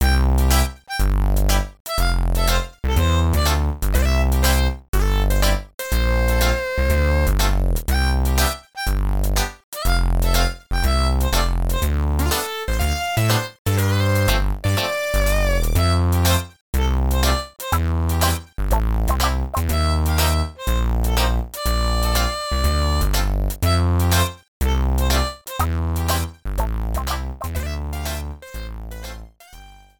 Ripped with Nitro Studio 2
Cropped to 30 seconds, fade out added